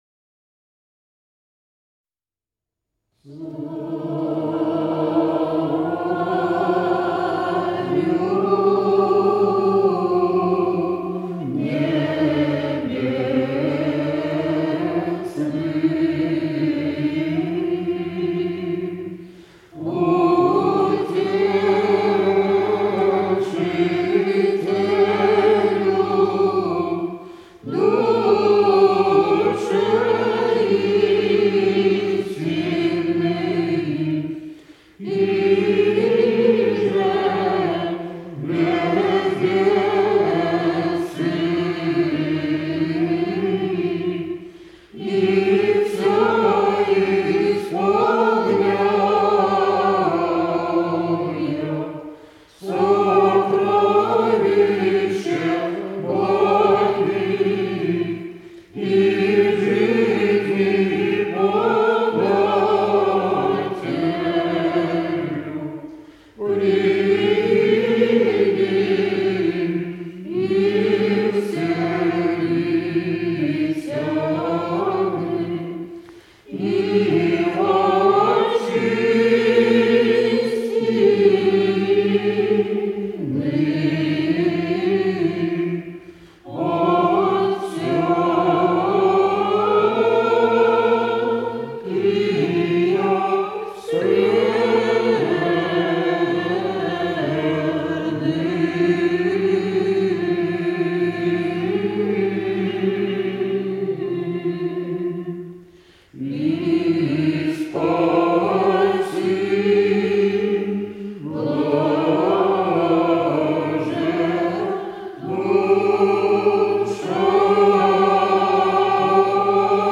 Певческое искусство старообрядческого хора села Стрельниково Костромской области
Одним из главных принципов стрельниковцев всегда были повышенное внимание к литургическому слову и как следствие — безупречная дикция. Распев одного слога всегда звучит плавно, согласные же на границе слогов четко отделены друг от друга.
01 Стихира «Царю небесный» 6-го гласа знаменного роспева в исполнении хора общины Русской православной старообрядческой церкви из с. Стрельниково Костромского р-на Костромской обл.